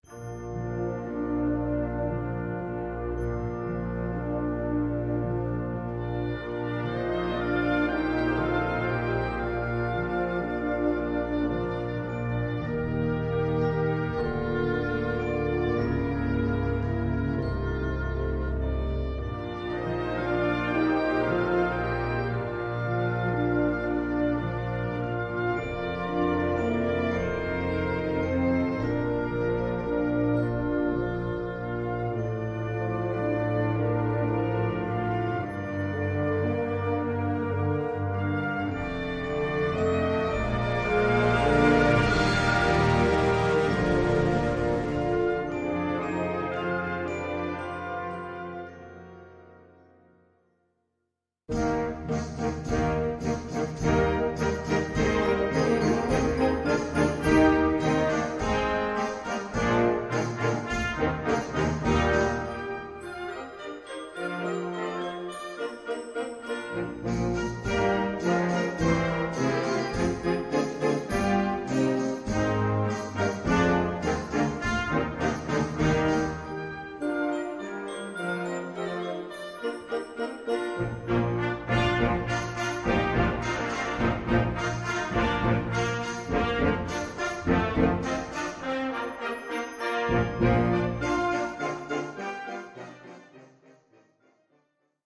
2:10 Minuten Besetzung: Blasorchester Zu hören auf
man hört sogar norwegische Instrumente erklingen.